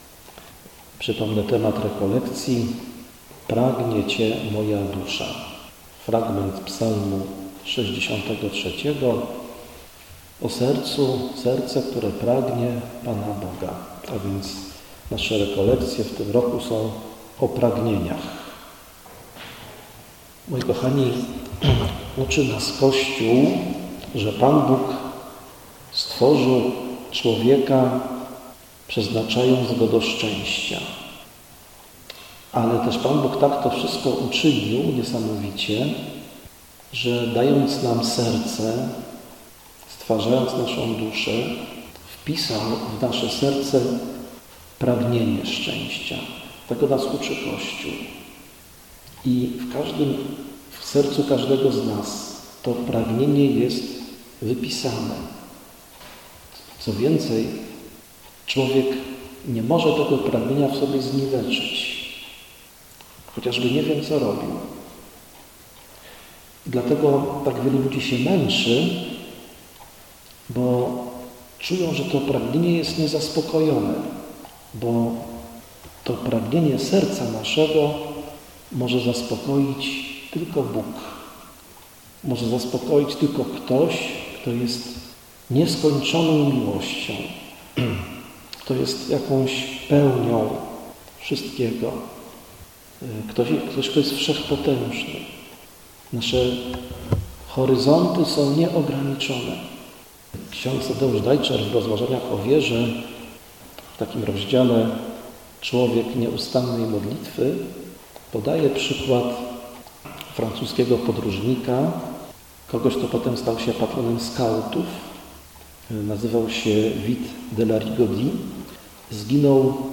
Zapraszamy do korzystania z nagrań konferencji wygłoszonych podczas tegorocznych rekolekcji wakacyjnych RRN.